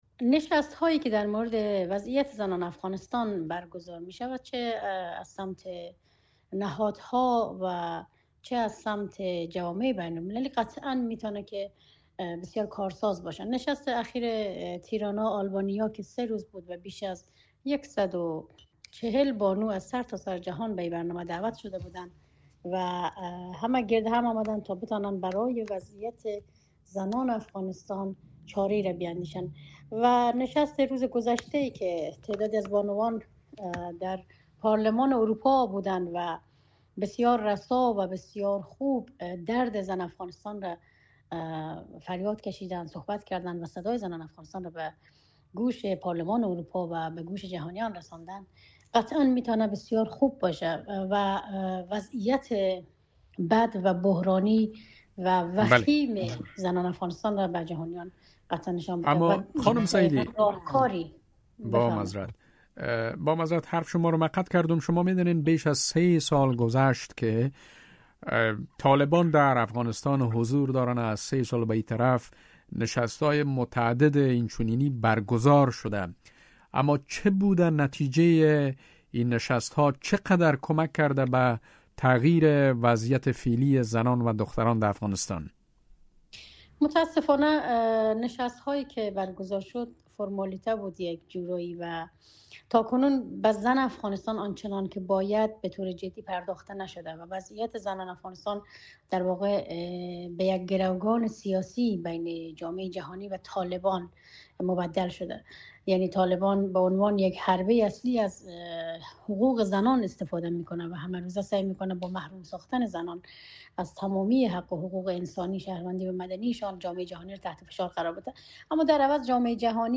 فعال حقوق زن